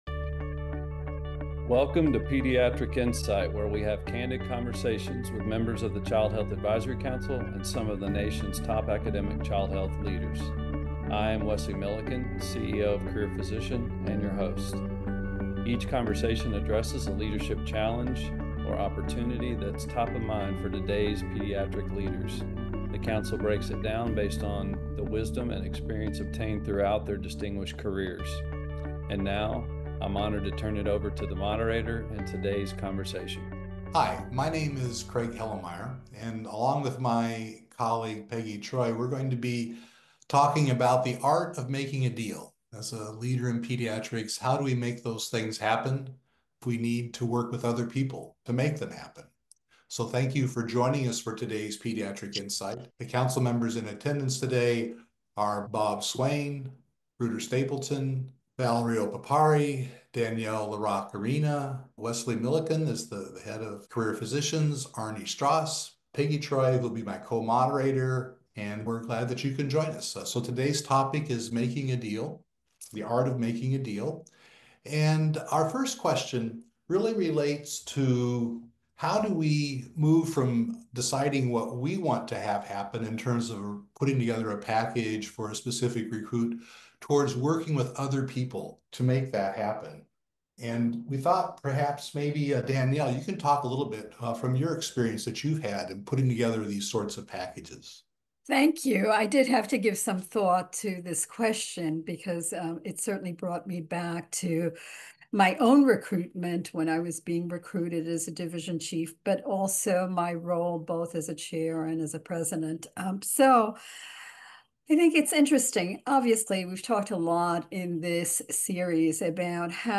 How do you approach negotiations with candidates? Hear from this esteemed group of emeritus pediatric leaders, who comprise the Child Health Advisory Council (CHAC), on what they prioritized, how they balanced internal needs, and non-traditional ways they put together packages that set the foundation for long-term success.